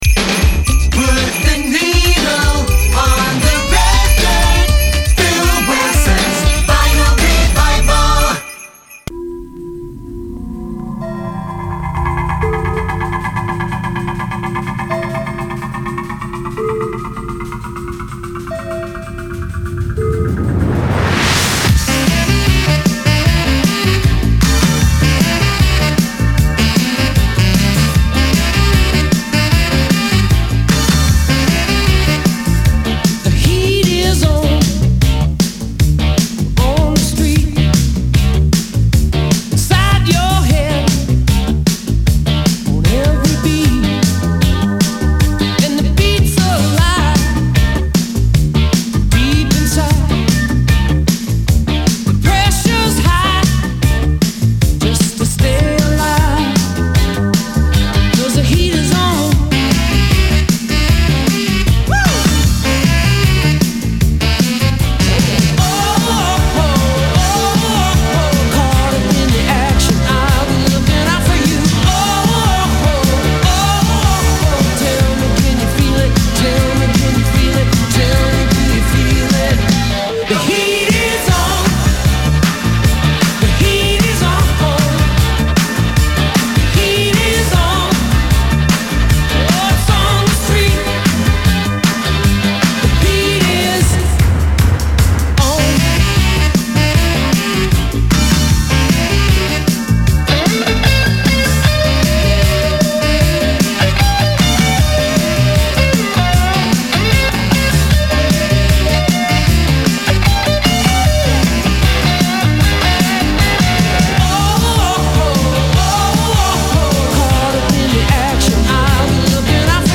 Playing The Greatest Hits On Vinyl Record -